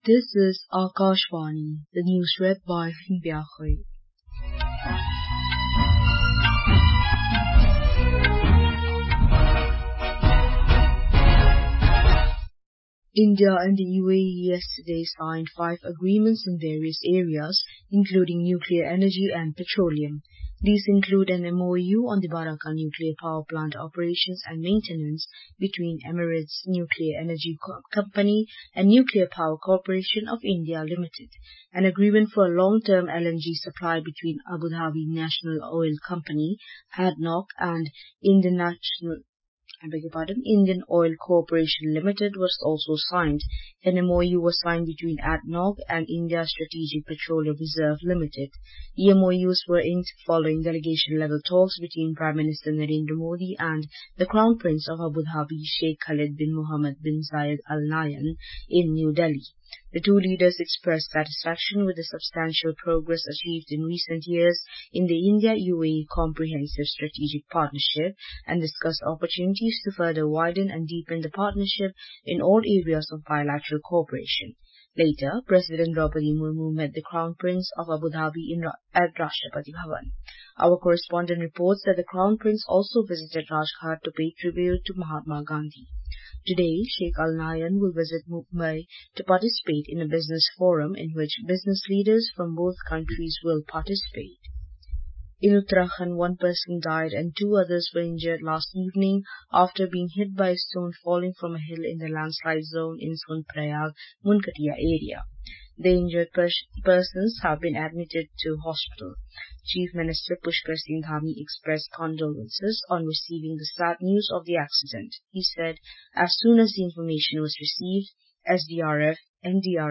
Hourly News
Hourly News | English